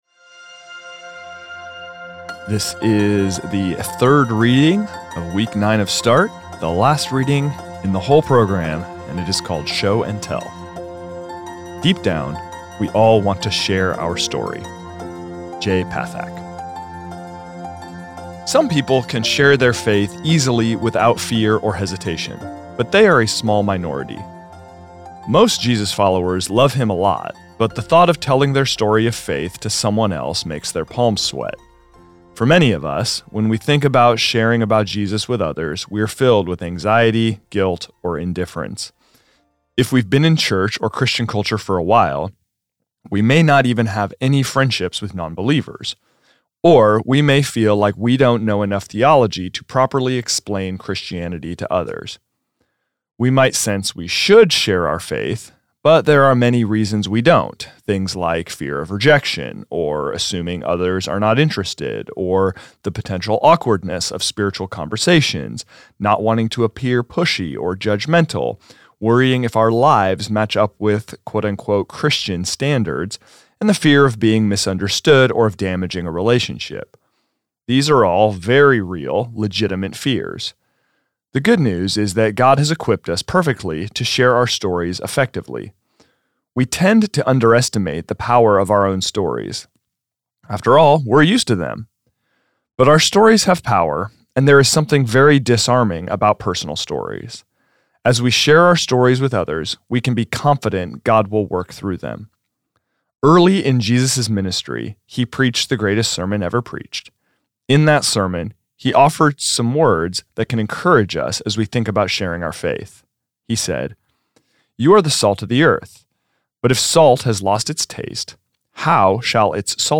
This is the audio recording of the third reading of week nine of Start, entitled Show and Tell.